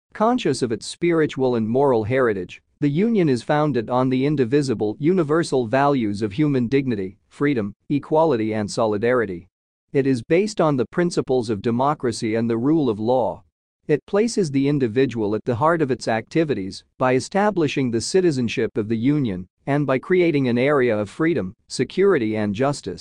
Our Voice Over Portfolio